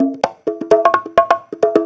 Conga Loop 128 BPM (23).wav